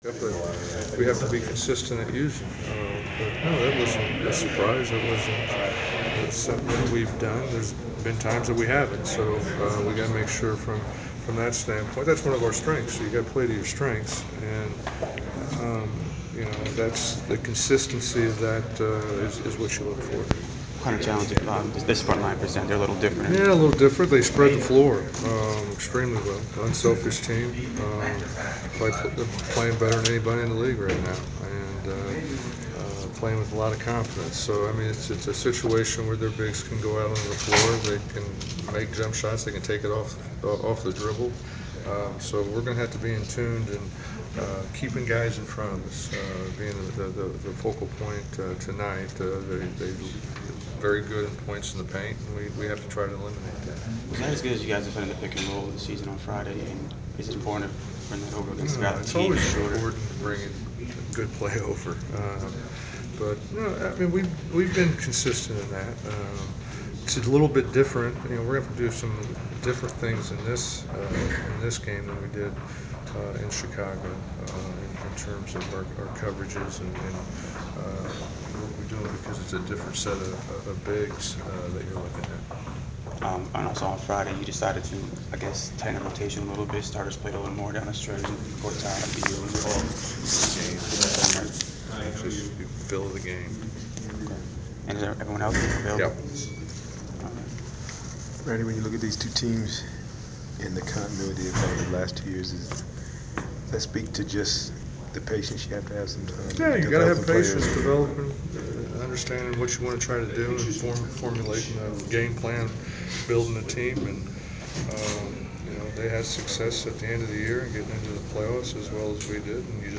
Inside the Inqurier: Pregame presser with Washington Wizards’ coach Randy Whitman (1/11/15)
whitman-wizards-coach-pregame.wav